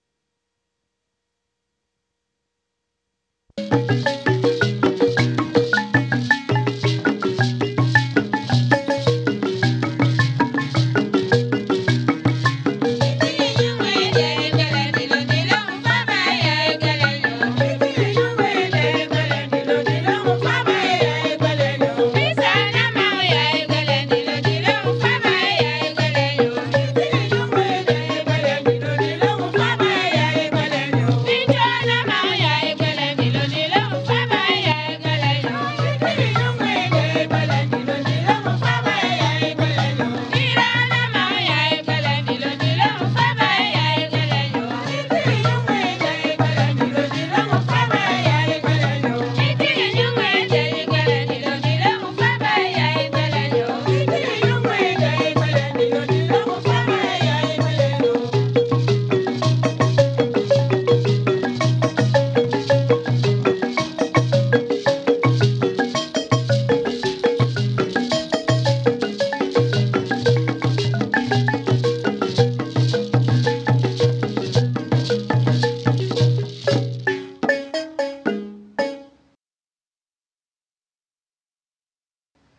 balafoniste
Ces chants sont des chants chrétien.